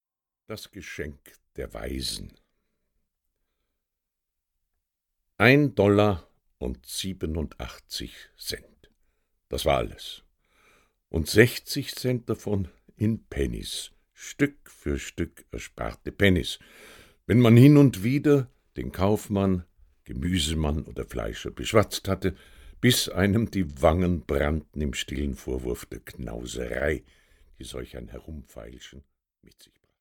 Die schönsten Weihnachtsgeschichten und -gedichte gelesen von Elmar Gunsch, Karl Heinrich Waggerl, Gunther Emmerlich, u.a.